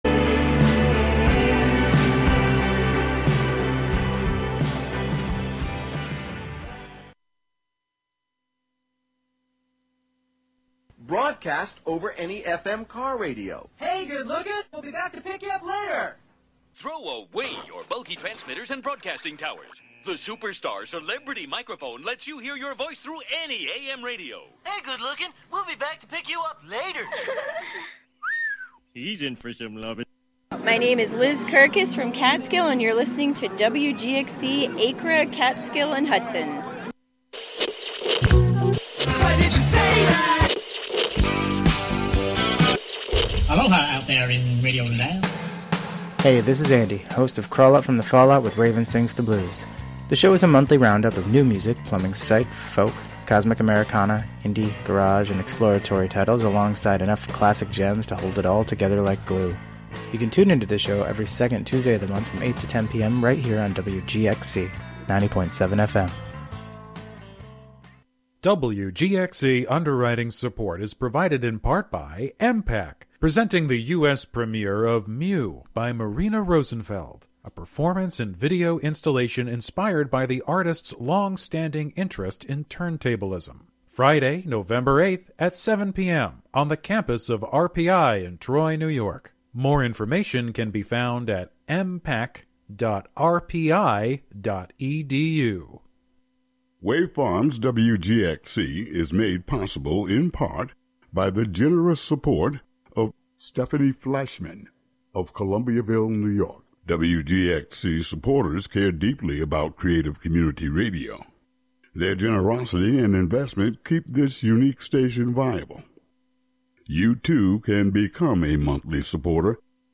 I like to start them out at 125 bpm and just keep building.